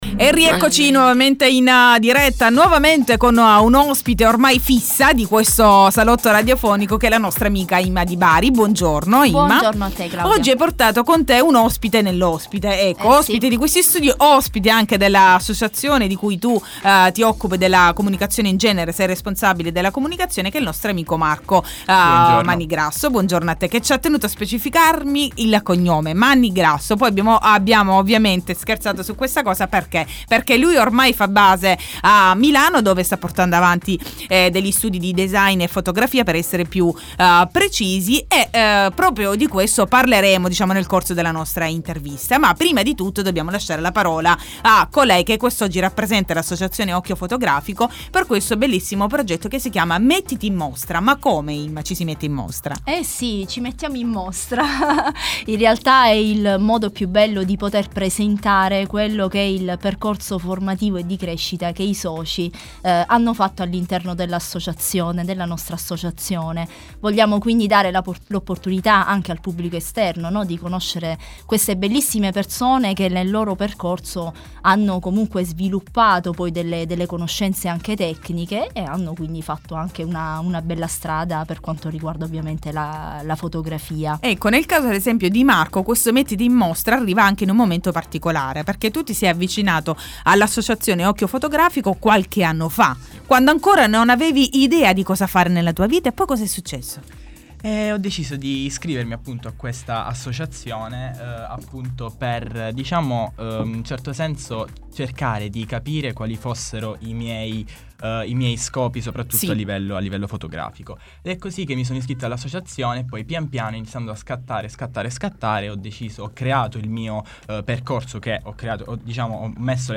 intervista-occhio-fotografico.mp3